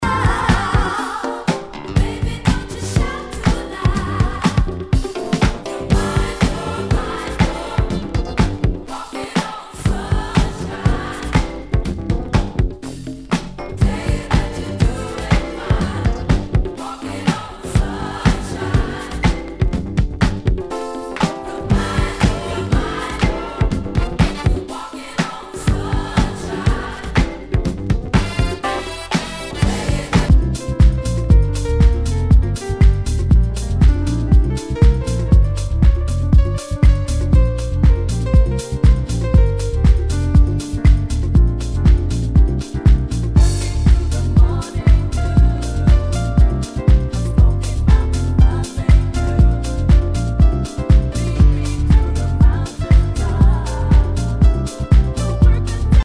HOUSE/TECHNO/ELECTRO
ナイス！ディスコ・ハウス・ミックス！
全体にチリノイズが入ります。